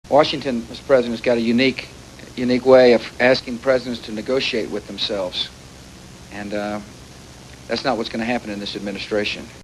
Bush tries to explain to Mexican president Vincente Fox how democracy will work in DC under his rule: